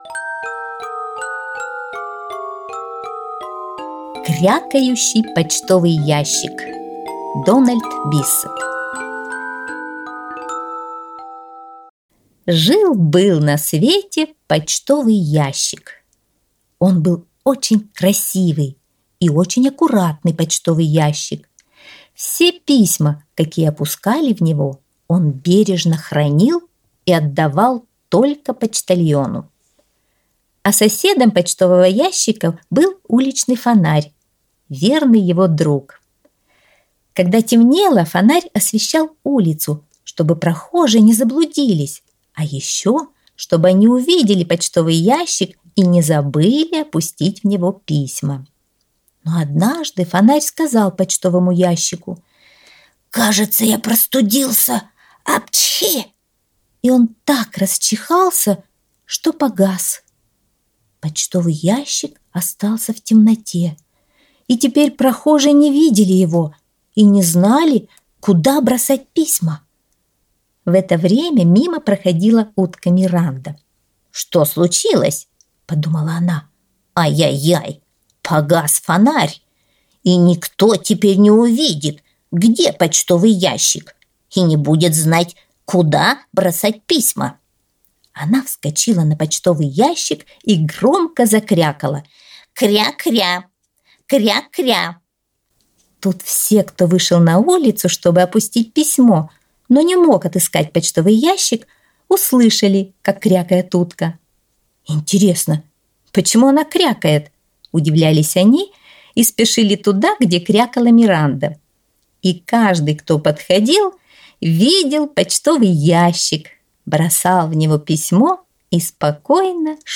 Аудиосказка «Крякающий почтовый ящик»